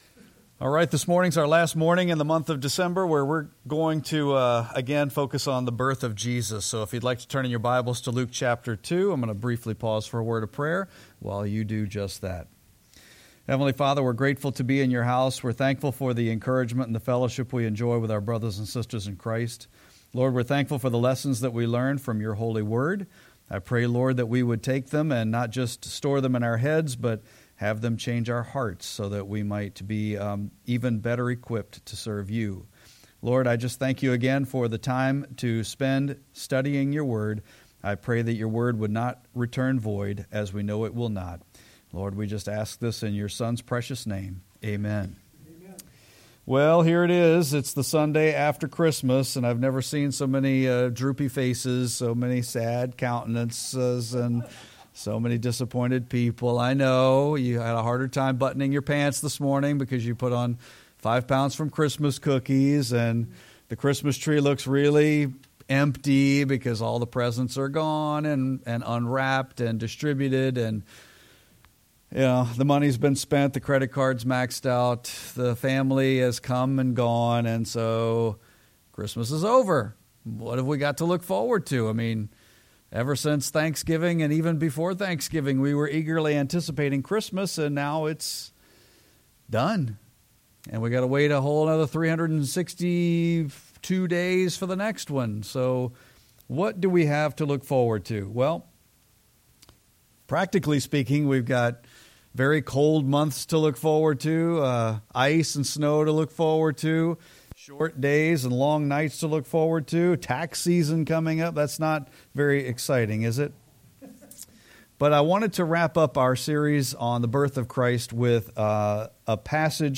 (Luke 2:21-38) – Freedom Baptist Church